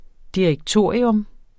Udtale [ diɐ̯əgˈtoˀɐ̯iɔm ]